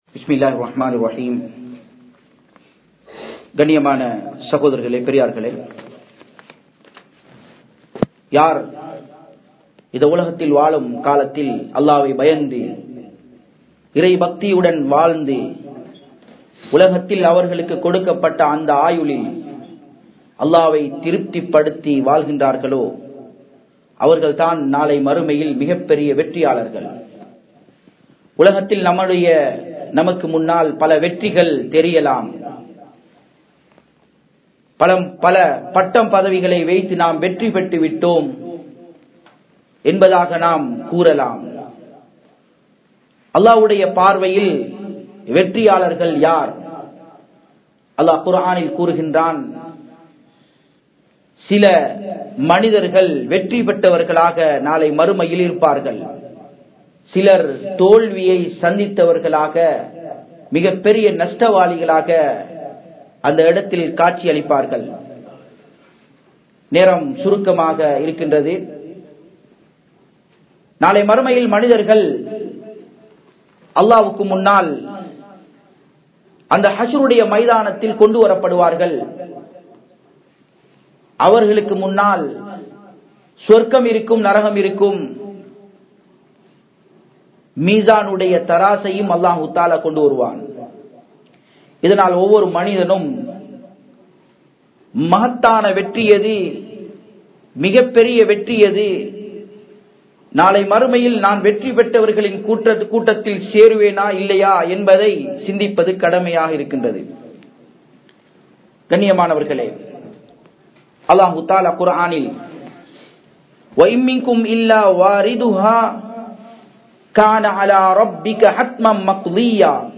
Mesaanukku Munaal Manithanin Nilai (மீஸானுக்கு முன்னால் மனிதனின் நிலை) | Audio Bayans | All Ceylon Muslim Youth Community | Addalaichenai